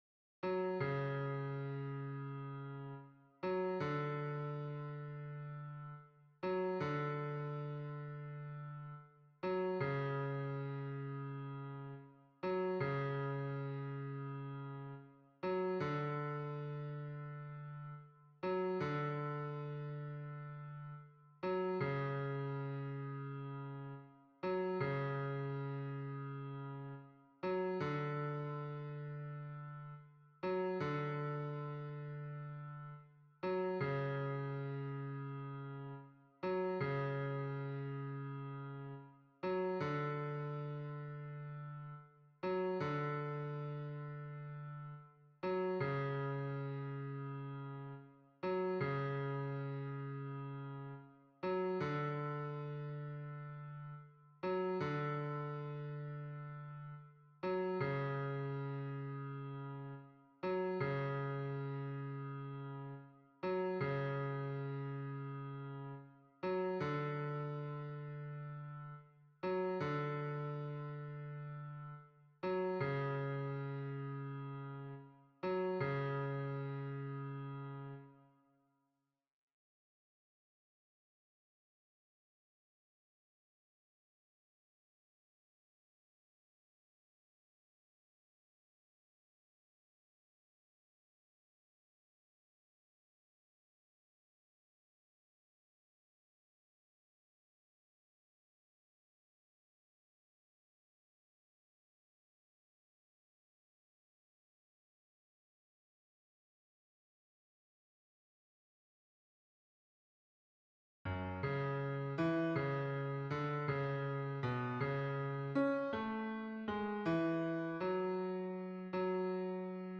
- berceuse traditionnelle norvégienne
MP3 version piano
Baryton